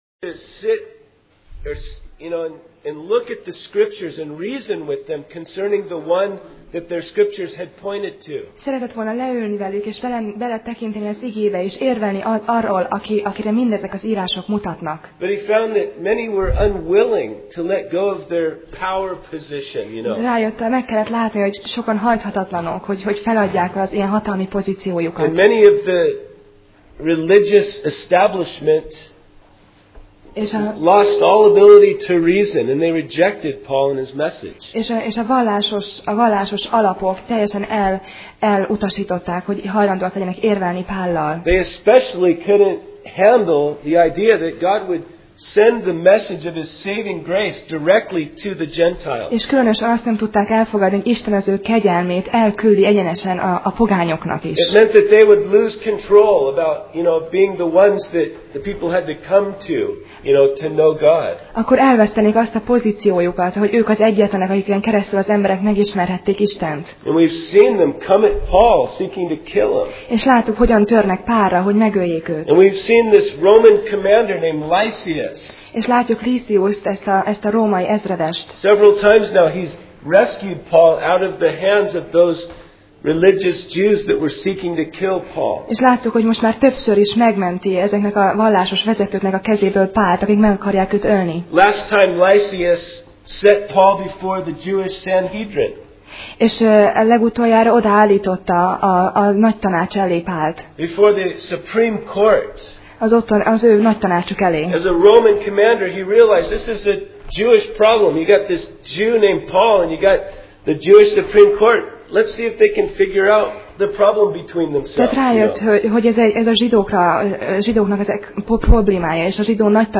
Sorozat: Apostolok cselekedetei Passage: Apcsel (Acts) 23:23-24:14 Alkalom: Vasárnap Reggel